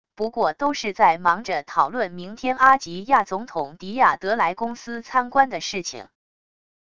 不过都是在忙着讨论明天阿及亚总统迪亚得来公司参观的事情wav音频生成系统WAV Audio Player